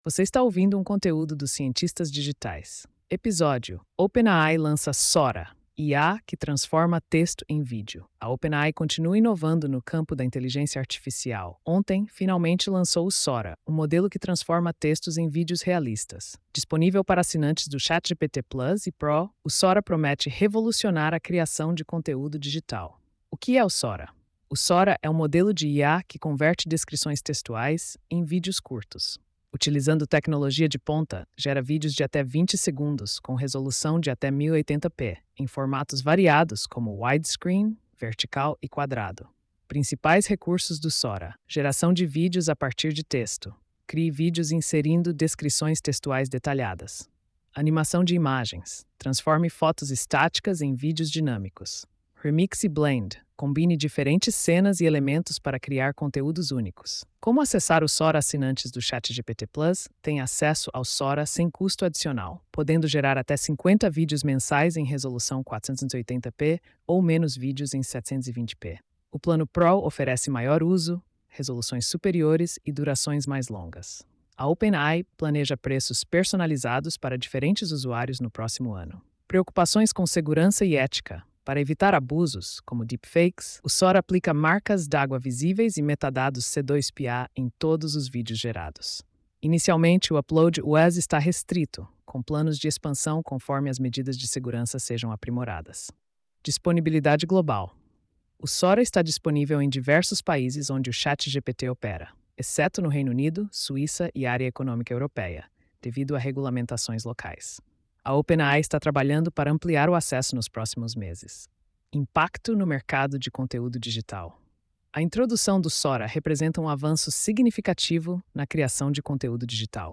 post-2659-tts.mp3